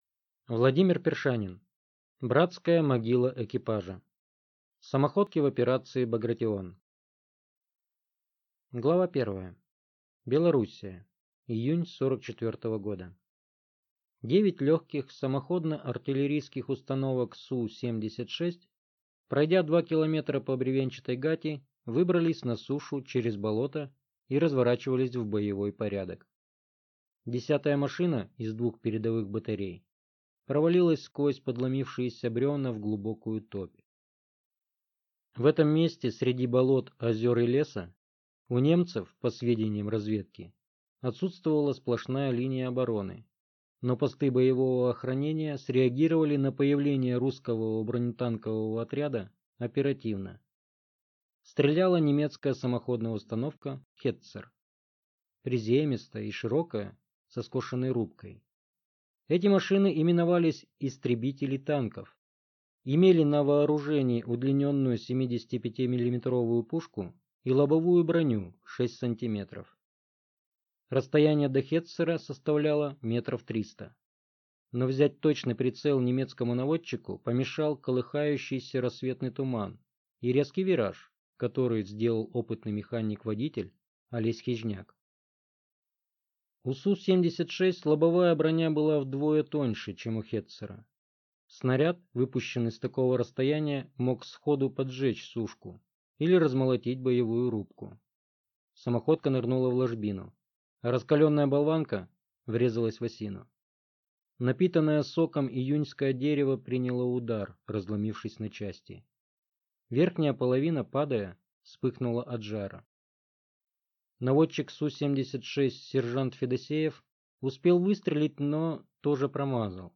Аудиокнига «Братская могила экипажа». Самоходки в операции «Багратион» | Библиотека аудиокниг